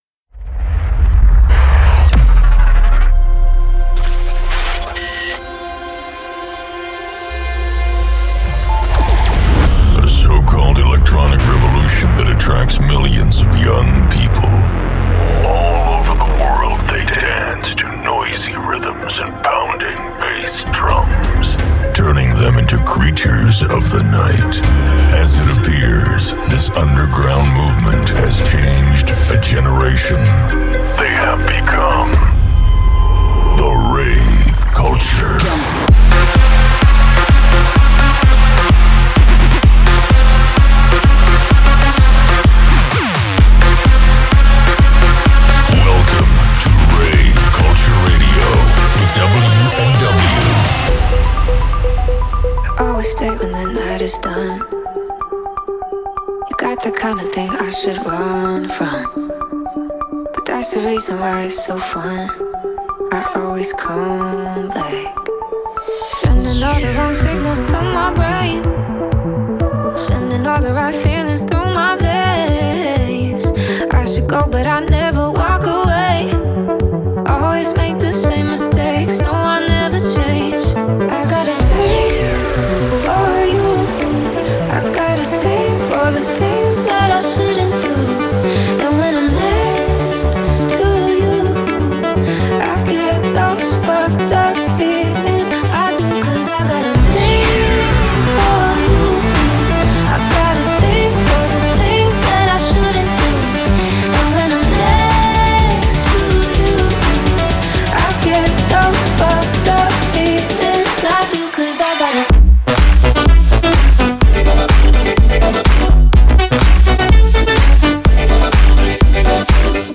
EDM mix
Trance